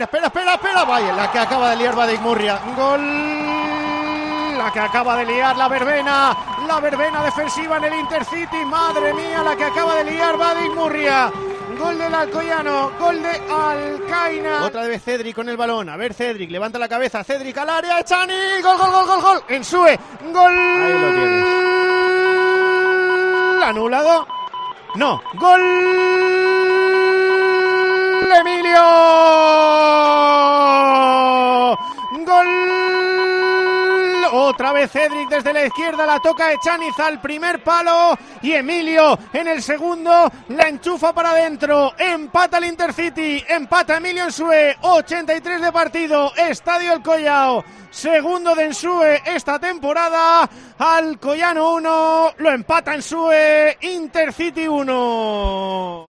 Sonidos del Alcoyano 1-1 Intercity